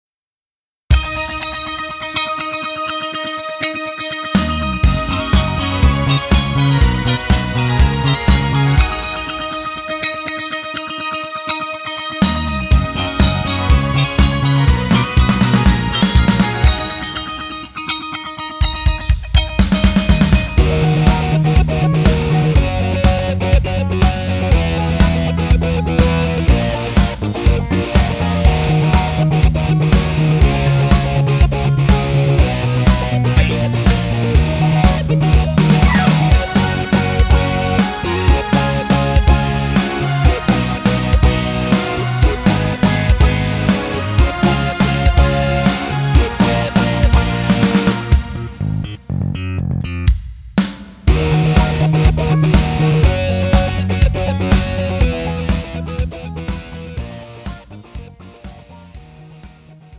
음정 -1키
장르 가요 구분 Pro MR
Pro MR은 공연, 축가, 전문 커버 등에 적합한 고음질 반주입니다.